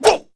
wrench_alt_fire5.wav